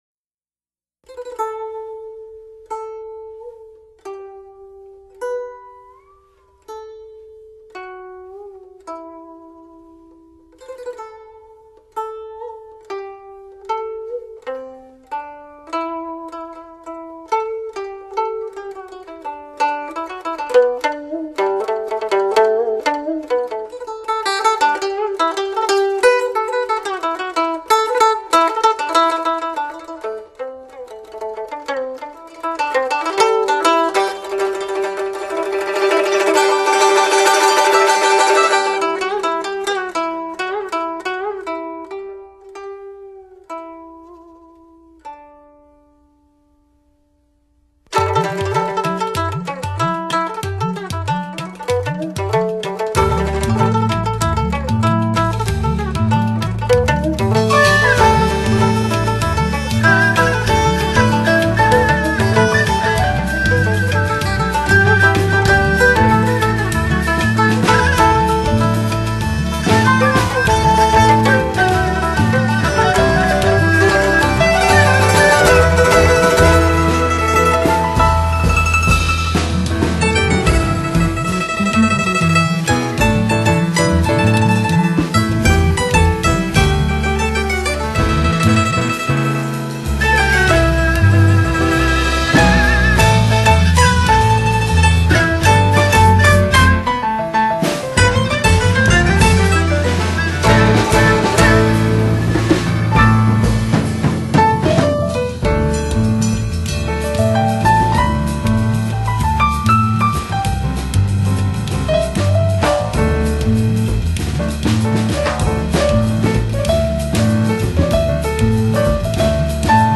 音樂類別：各种乐器
東方樂器 西方爵士 和非洲人聲歌詠